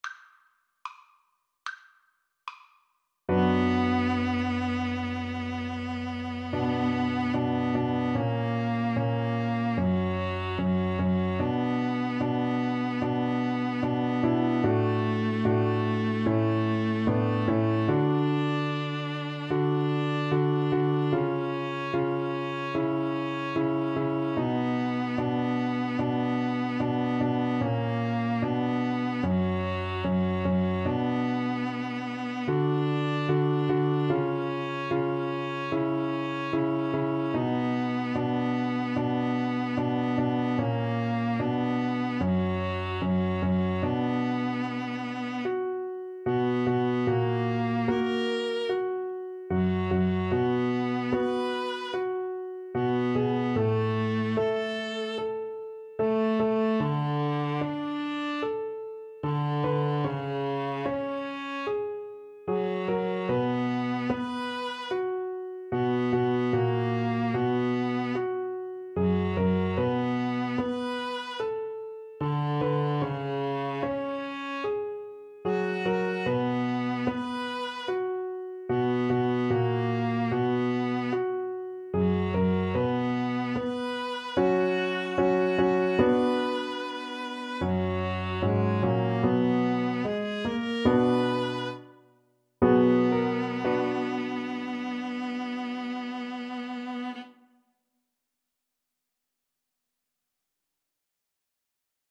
Allegretto = c. 74
2/4 (View more 2/4 Music)
Viola Duet  (View more Easy Viola Duet Music)
Classical (View more Classical Viola Duet Music)